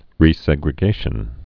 (rē-sĕgrĭ-gāshən)